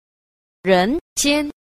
3. 常人間 – cháng rénjiān – thường nhân gian (nhân gian)